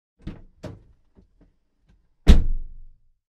Car Door Open and Close